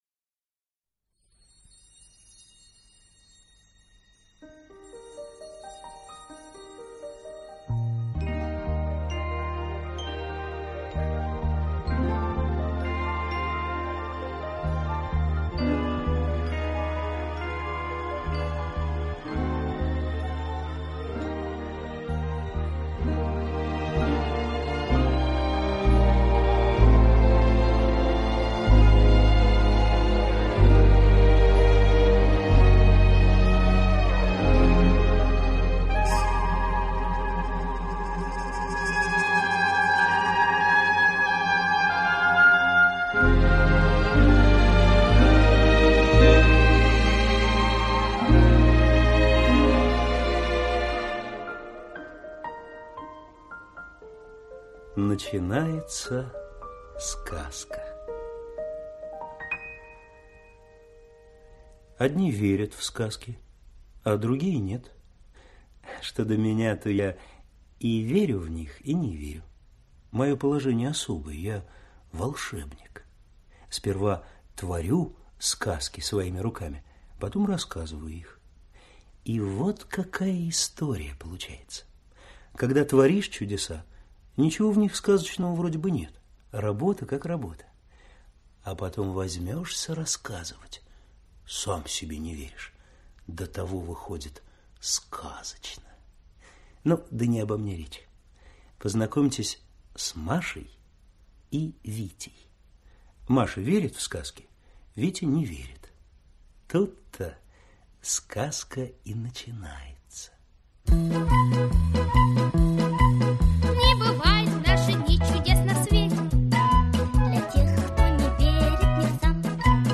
Маша и Витя против "Диких гитар" - аудио рассказ Яковлева Ю.Я. Кощей Бессмертный с помощь нечистой силы решил устроить шоу "Голос"...